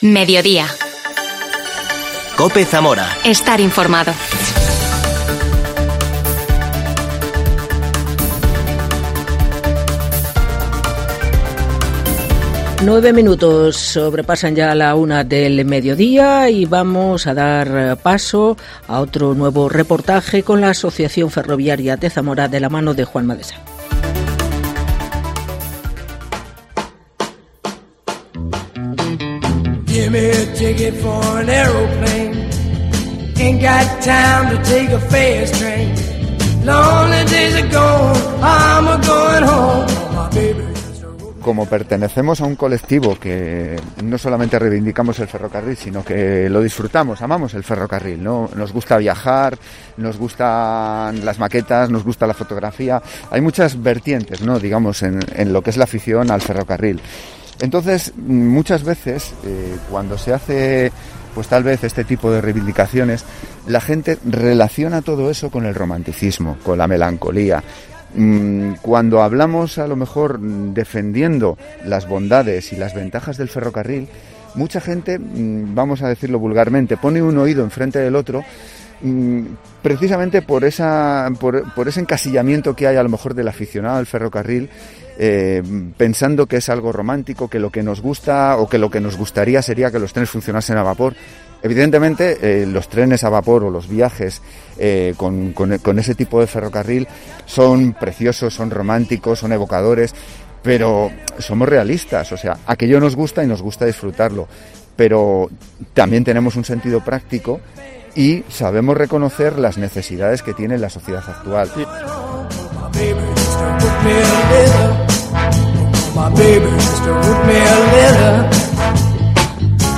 AUDIO: Reportaje de la Asociación Zamorana de Ferrocarril. III Parte